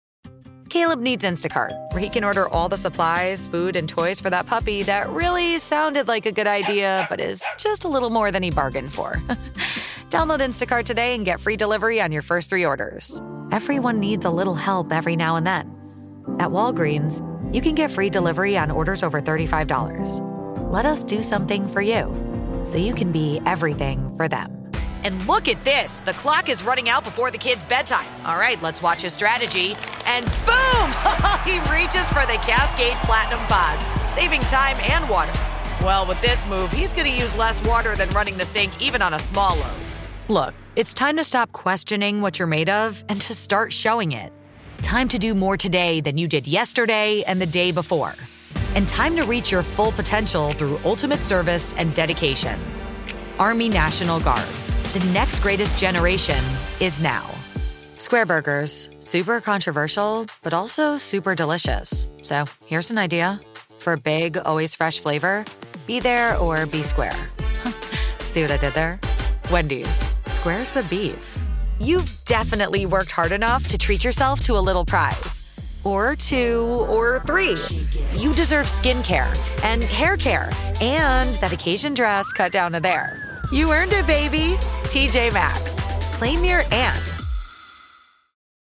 Conversacional
Amistoso
Cálido